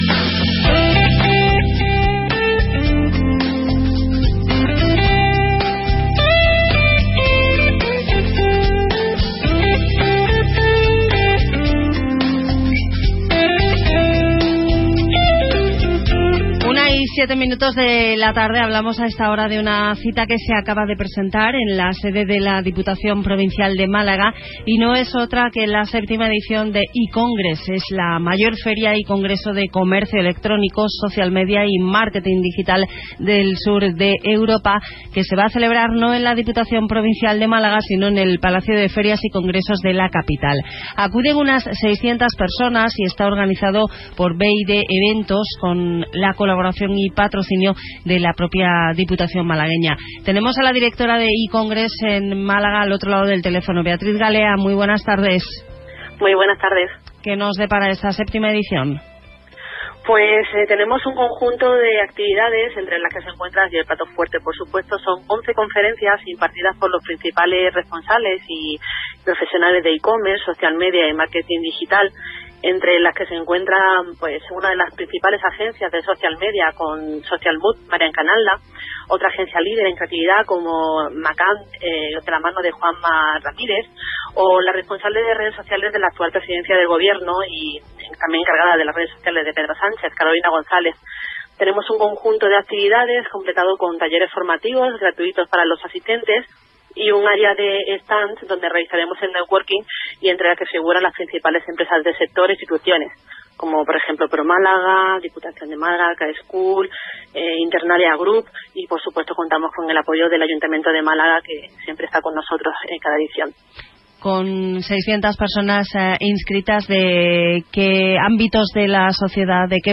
Entrevista 2019 para Onda Cero